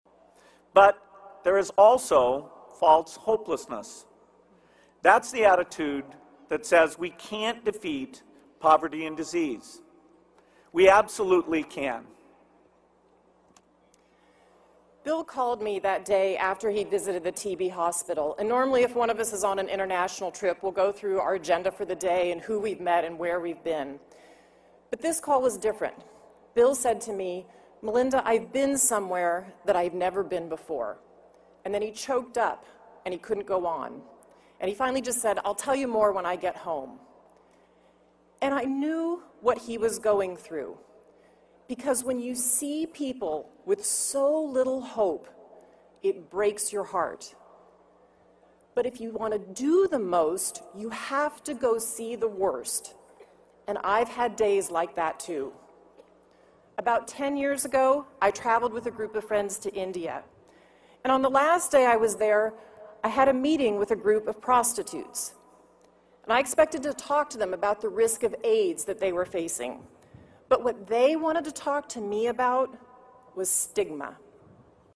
公众人物毕业演讲第29期:比尔盖茨夫妇于斯坦福大学(10) 听力文件下载—在线英语听力室